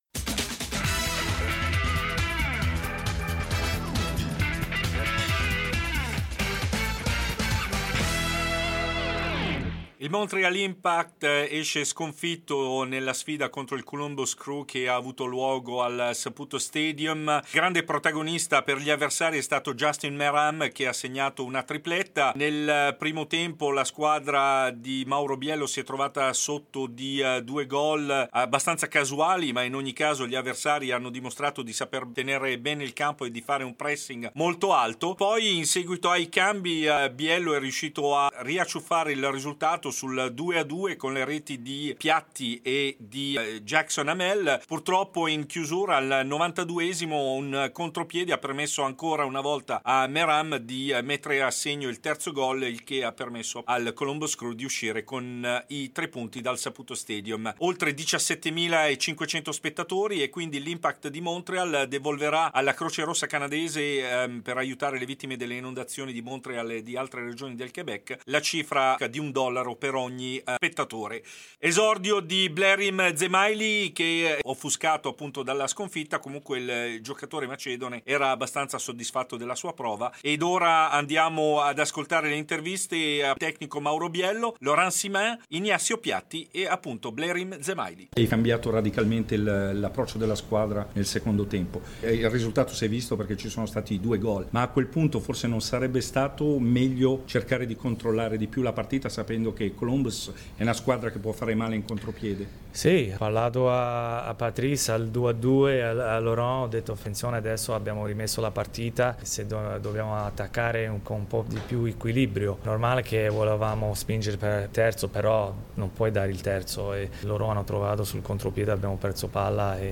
Servizio completo con le interviste post-partita